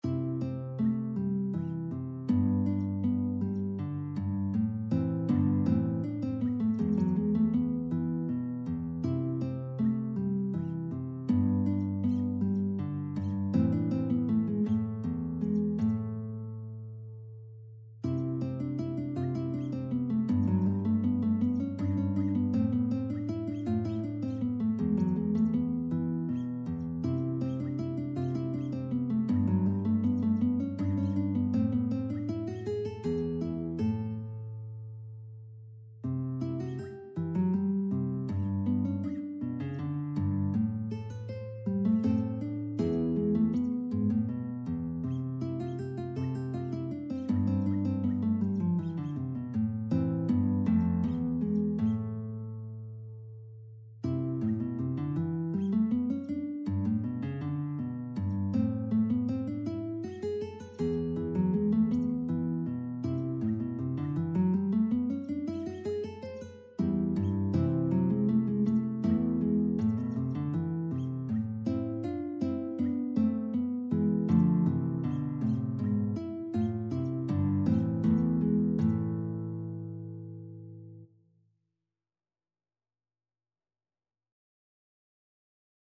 C major (Sounding Pitch) (View more C major Music for Guitar )
Andante
3/4 (View more 3/4 Music)
E3-C6
Guitar  (View more Intermediate Guitar Music)
Classical (View more Classical Guitar Music)